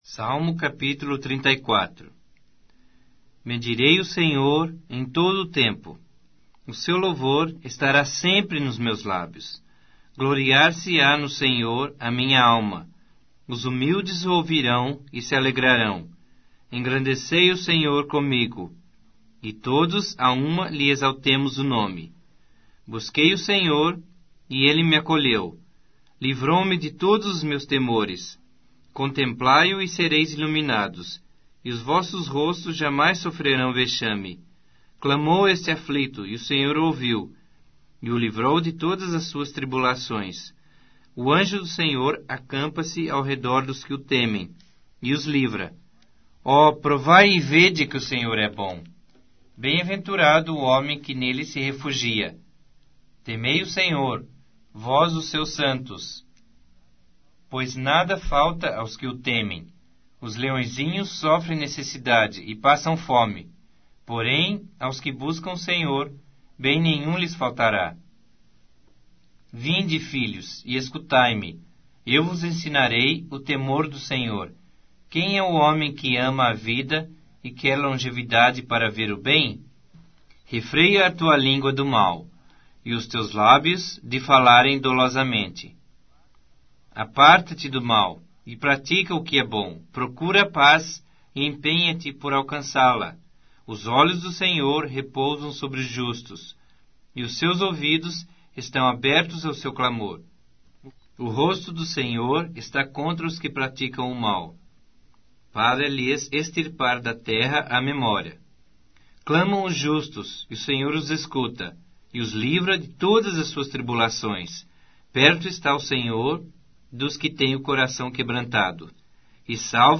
Bíblia Sagrada Online Falada